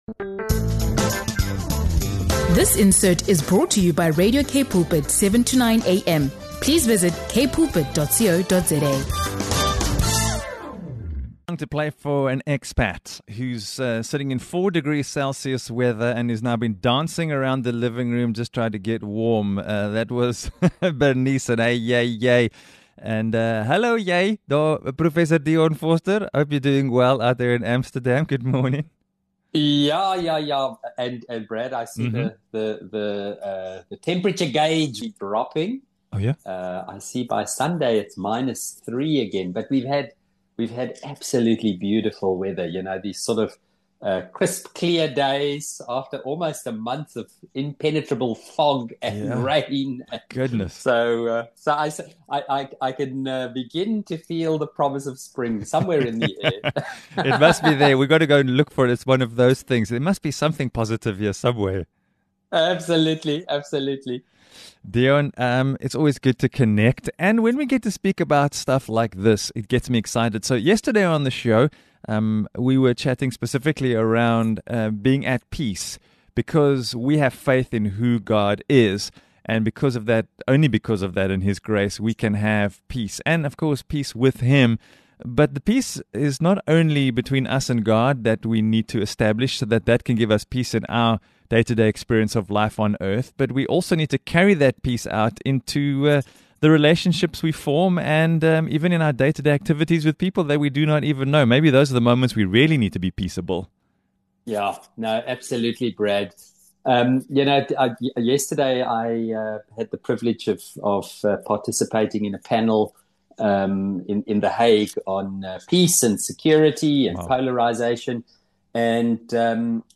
Tune in for an inspiring conversation on living out the call to be blessed peacemakers in a divided world.